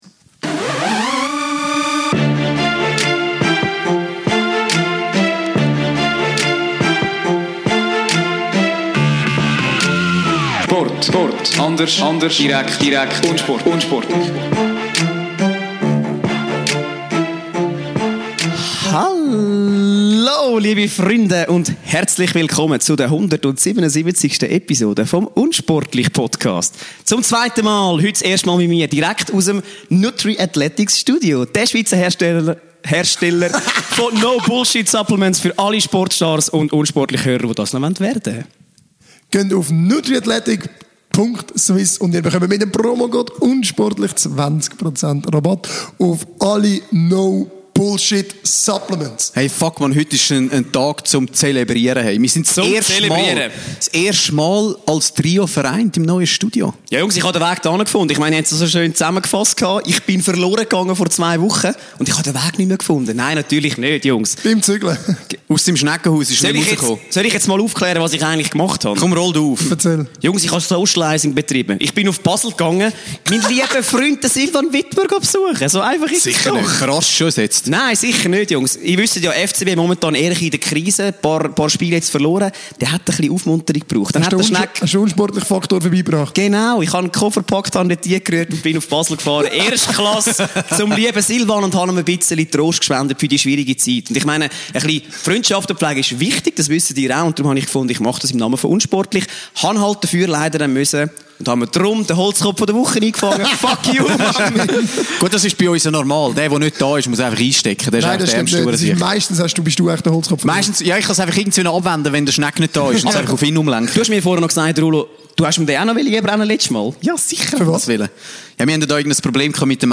#177: Mäuse im neuen Studio!
Diese Woche gibt er sein Comeback und das zum ersten Mal im Nutriathletics-Studio!